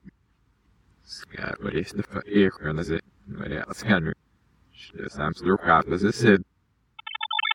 Sorry no more fancy animations :P (I rushed this a bit) egassemsdrawkcab.mp3 Submit send Answer: Backwards Message Part three revealed!
egassemsdrawkcab.mp3